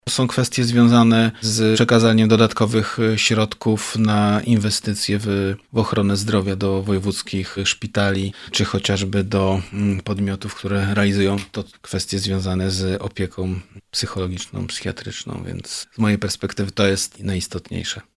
Jednym z punktów porządku obrad będzie kwestia finansów na psychiatrię w regionie, dodaje wicemarszałek.